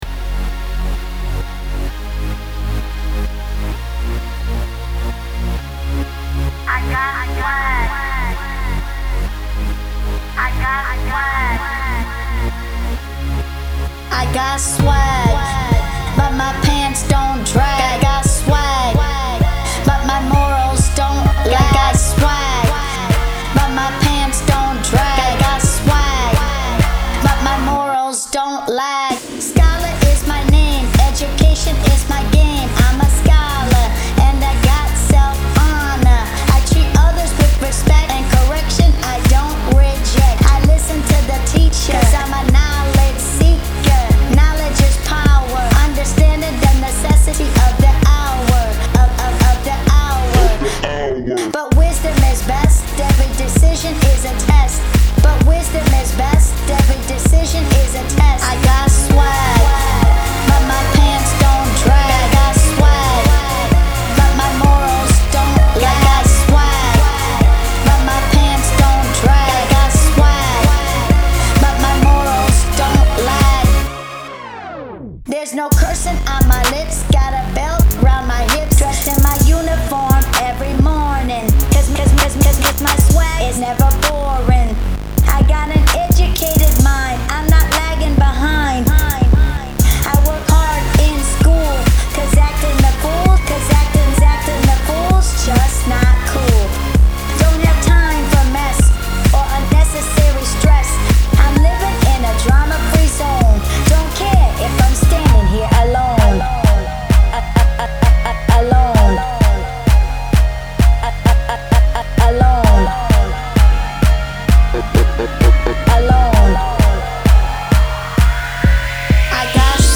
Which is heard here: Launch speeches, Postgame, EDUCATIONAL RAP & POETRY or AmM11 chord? EDUCATIONAL RAP & POETRY